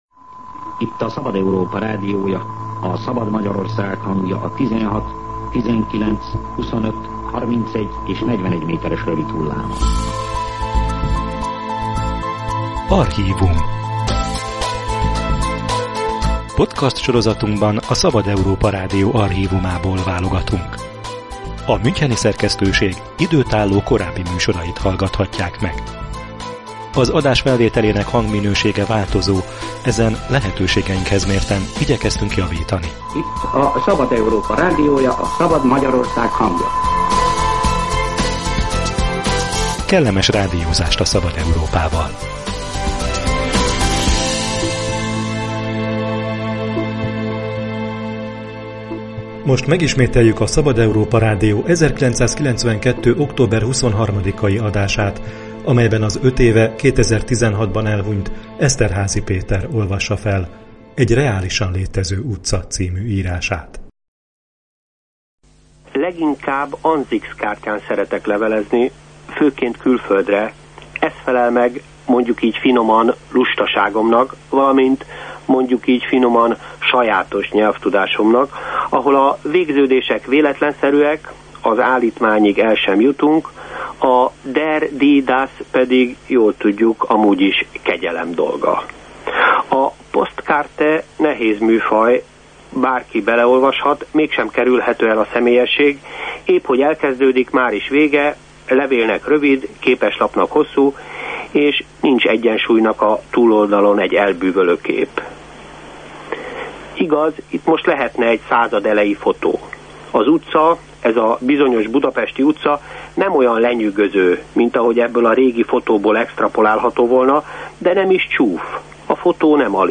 Új podcastsorozatot indítunk, amelyben elődünk, a Szabad Európa Rádió időtálló, korábbi műsorait elevenítjük fel. A hetente jelentkező sorozat első epizódjában az öt éve elhunyt Esterházy Péter olvassa fel Egy reálisan létező utca című írását.